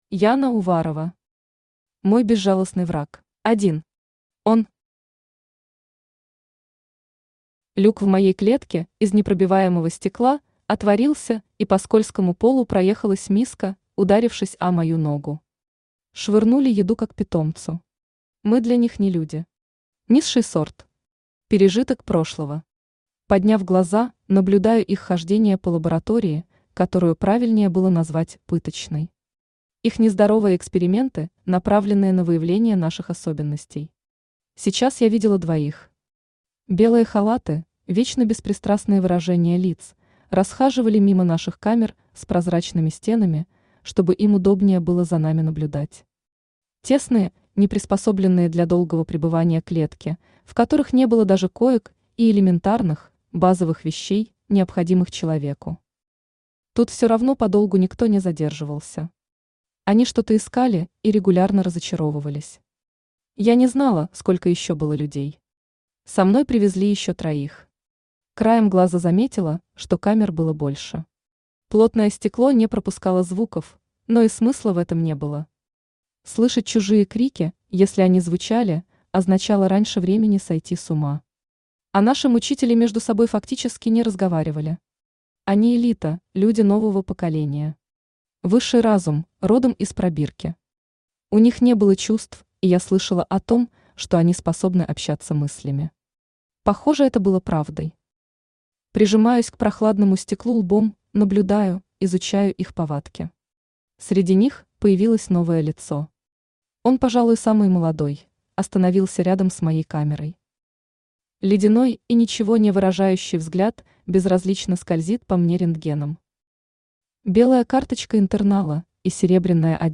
Аудиокнига Мой безжалостный враг | Библиотека аудиокниг
Aудиокнига Мой безжалостный враг Автор Яна Уварова Читает аудиокнигу Авточтец ЛитРес.